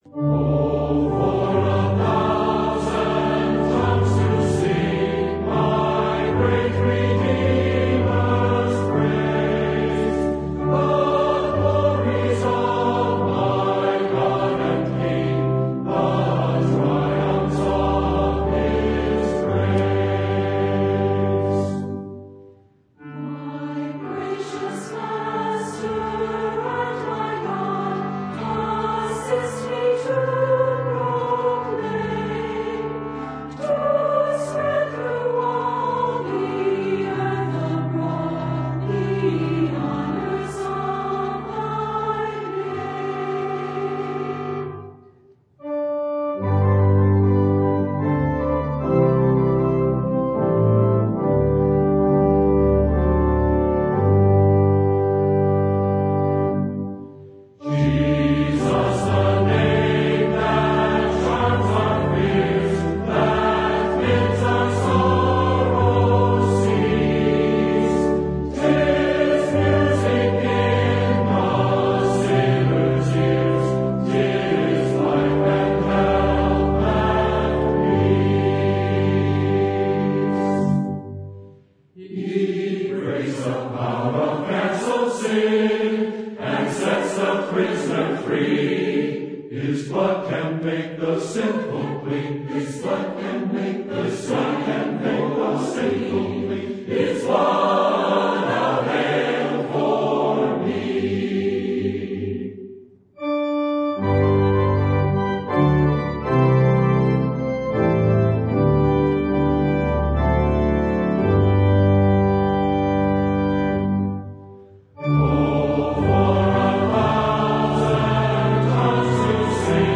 Hymns
Chancel Choir - Hymn Samples.mp3